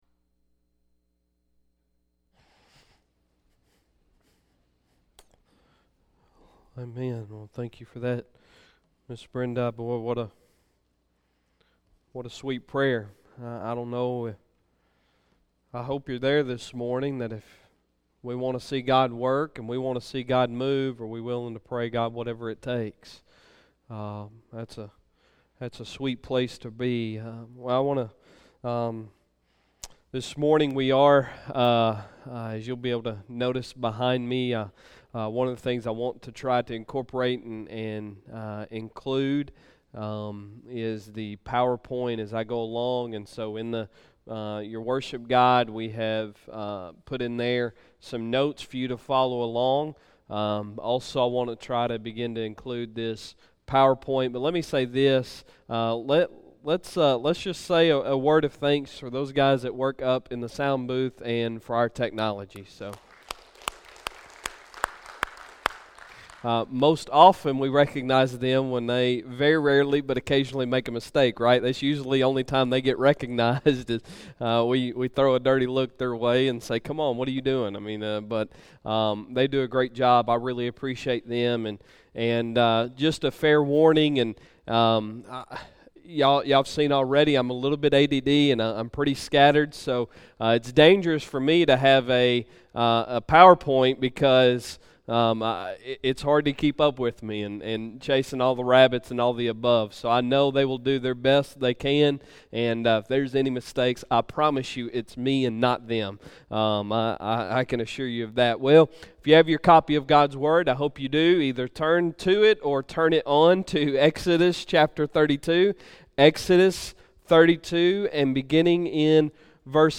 Sunday Sermon: July 1, 2018
Sermons